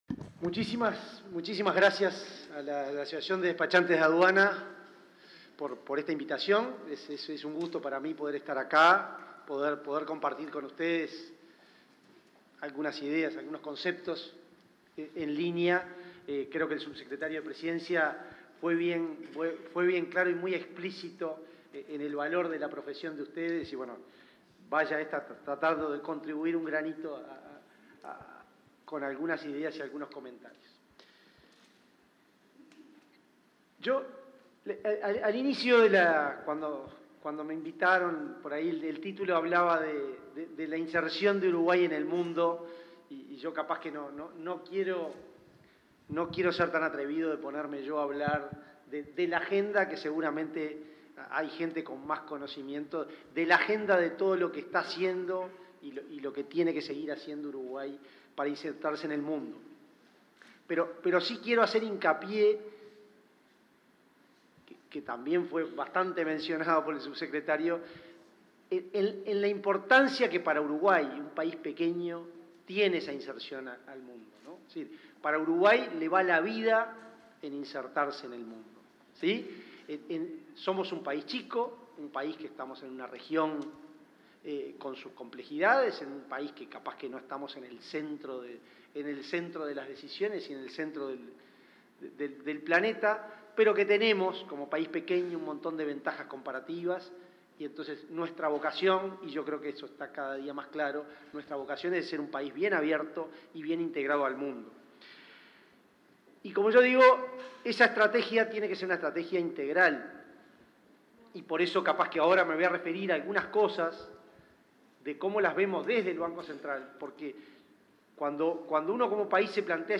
Palabras del presidente del BCU
El presidente del Banco Central del Uruguay (BCU), Diego Labat, participó del primer Congreso de Actualización de Comercio Exterior, este jueves 27 en